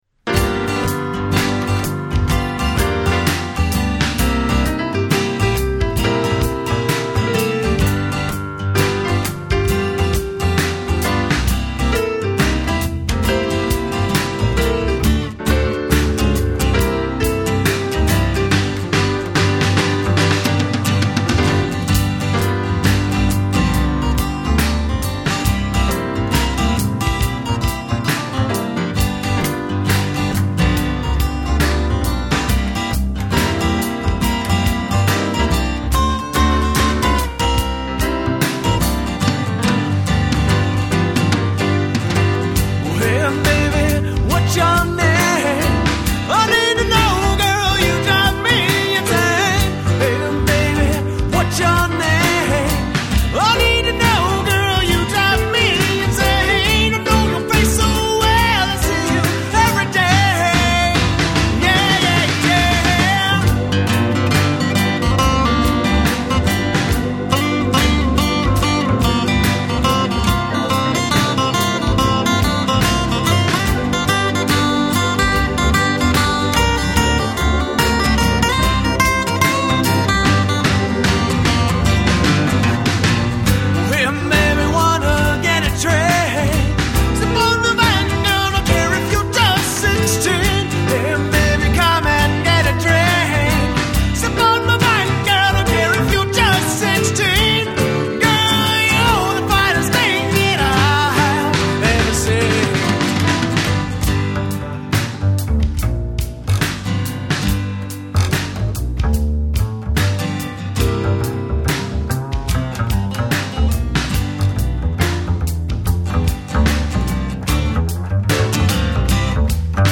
Guitar, Harmonica, Vocals
Drums
Piano, Organ
Upright Bass